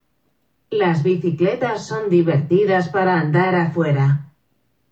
speak_bicycles_sp_fast.m4a